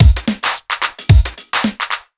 OTG_Kit 3_HeavySwing_110-D.wav